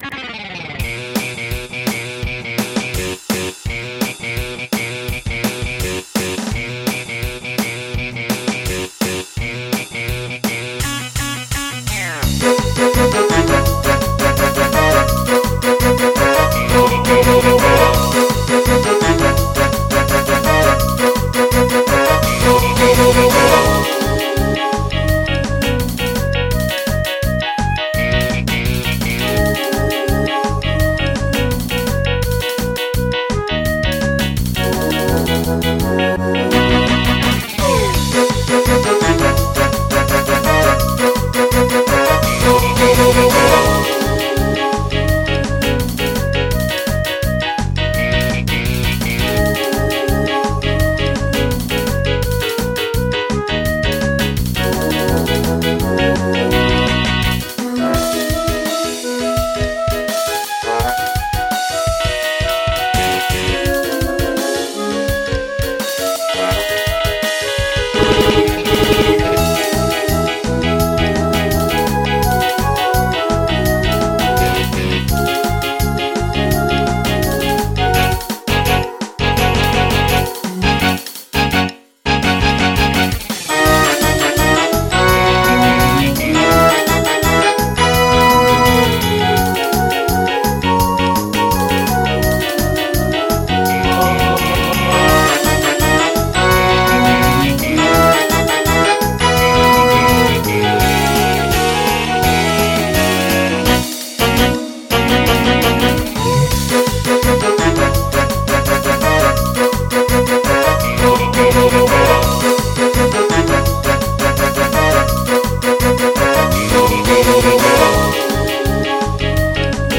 MIDI 87.34 KB MP3 (Converted) 3.61 MB MIDI-XML Sheet Music